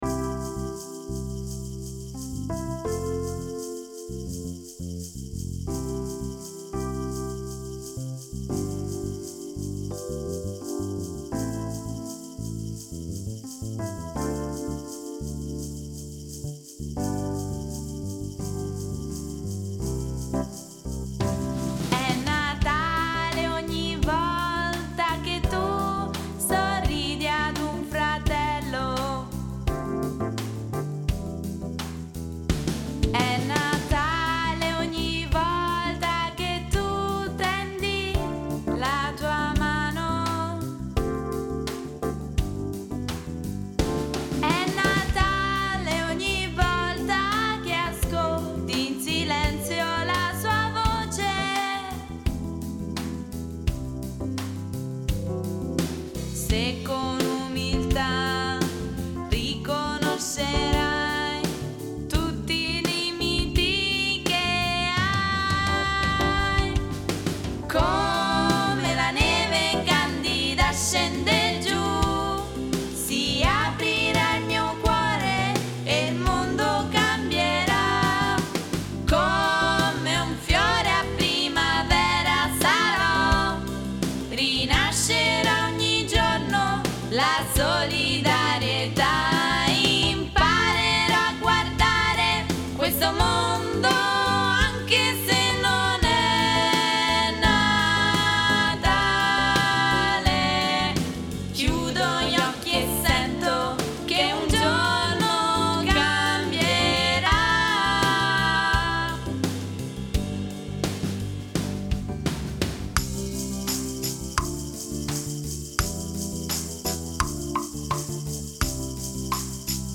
ORCHESTRA DIDATTICA
con accompagnamento di
percussioni "povere"
Voce solistacoro 2 voci
Basso Elettrico